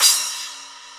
CYMBAL 12 08.wav